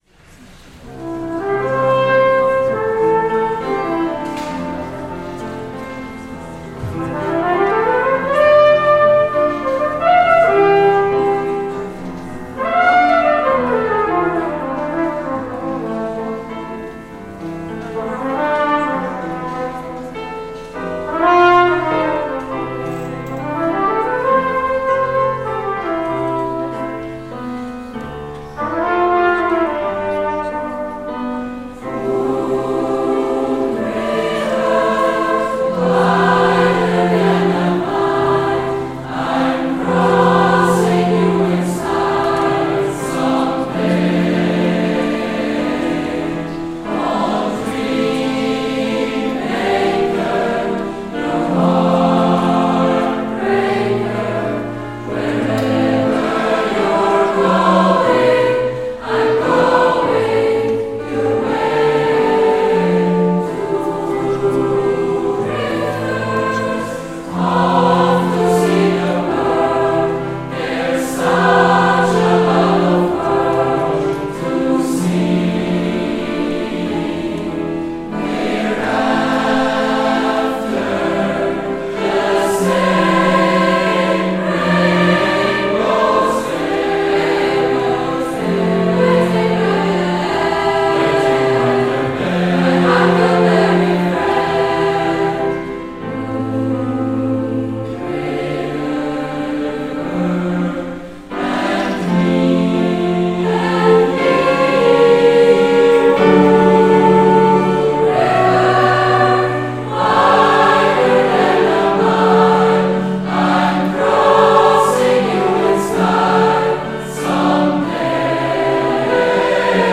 Sommerkonzert 2025 Sing, Sing, Sing!